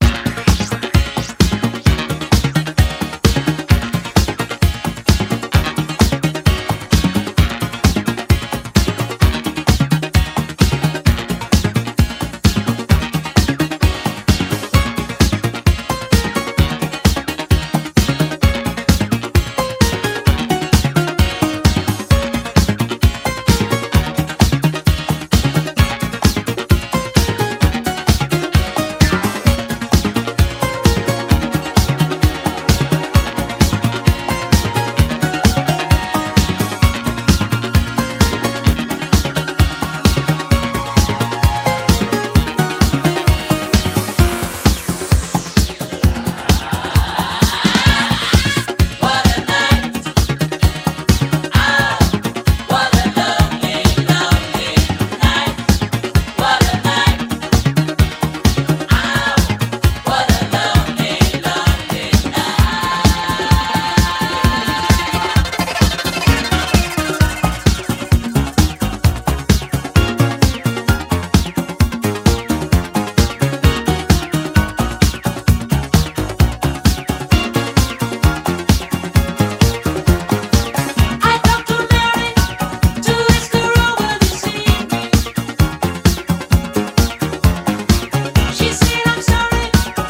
極上フィリーソウルをたっぷり収録！